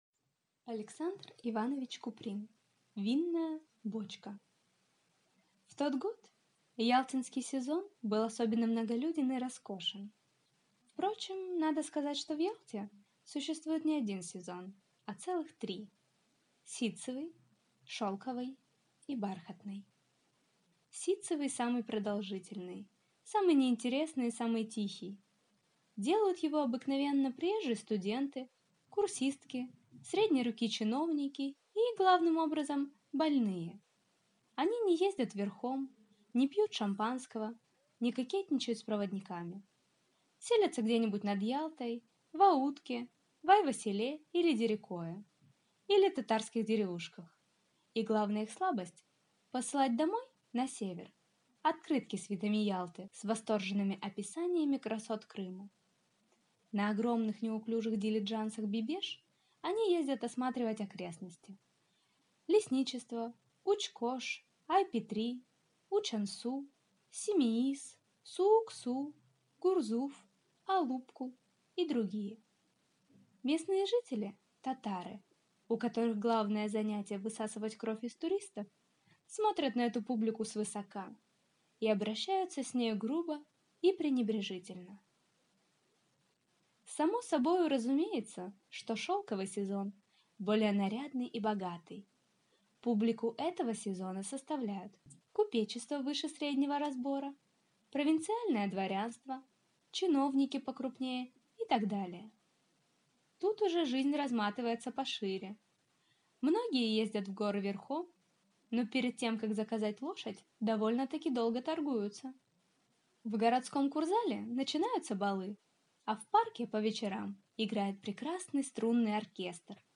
Аудиокнига Винная бочка | Библиотека аудиокниг